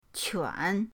quan3.mp3